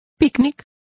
Complete with pronunciation of the translation of picnic.